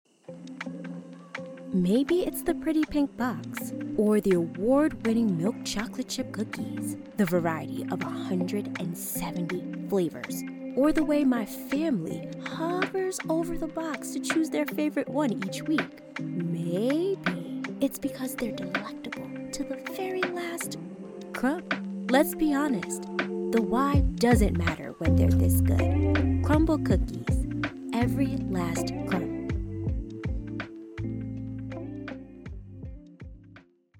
Commercial
My voice is cheerful, youthful. bright, distinctive and versatile.